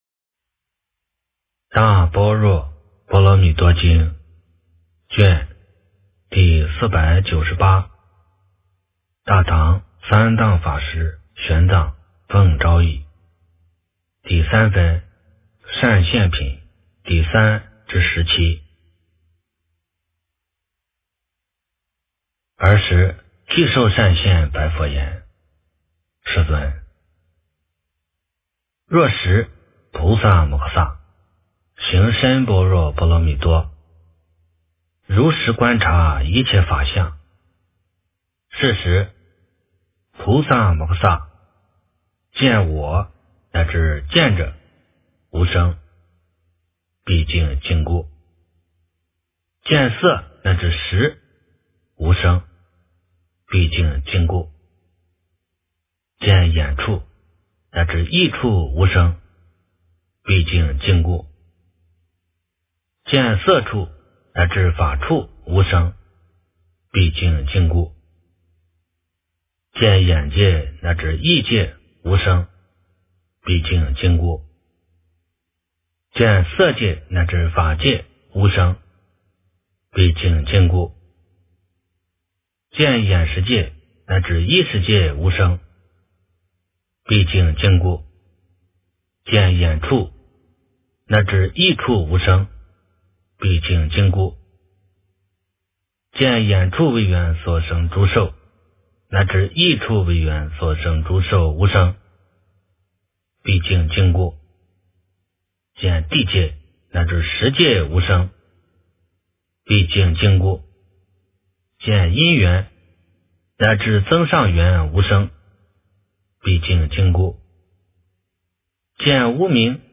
大般若波罗蜜多经第498卷 - 诵经 - 云佛论坛